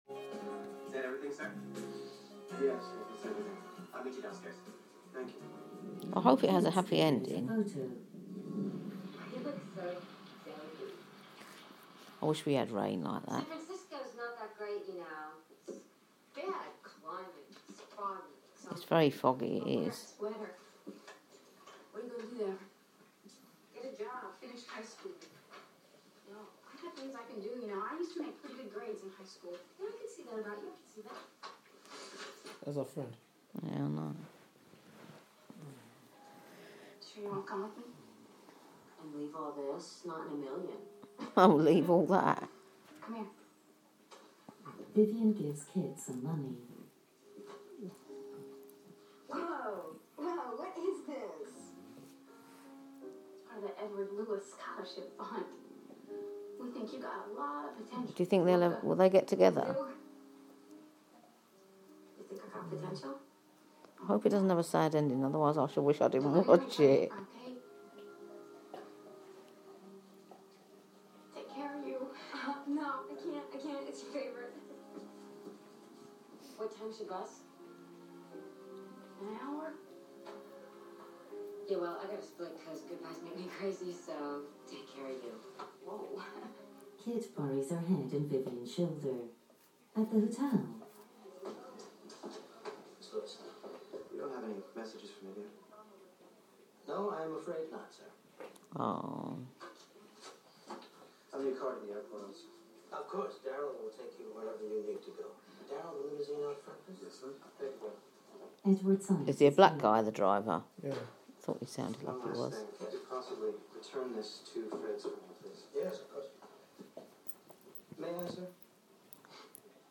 Watching The end of Pretty Woman with audio description